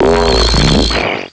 -Replaced the Gen. 1 to 3 cries with BW2 rips.
stunfisk.aif